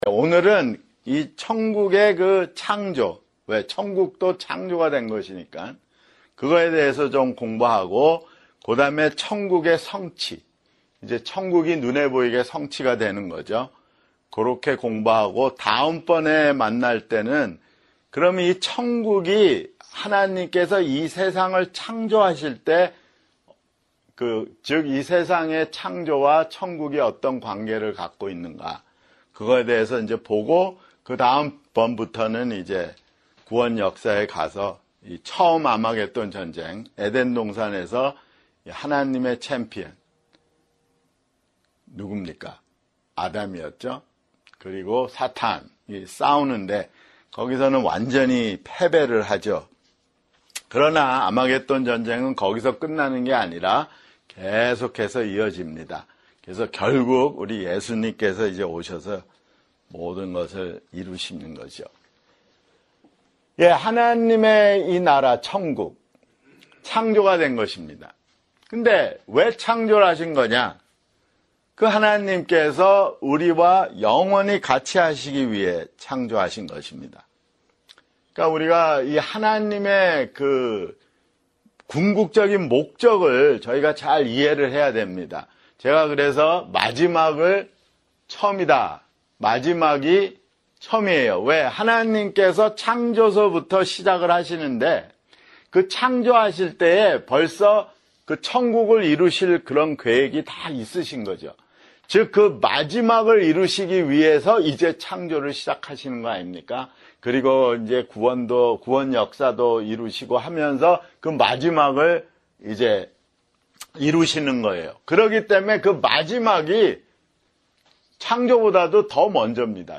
[성경공부] 아마겟돈 (3)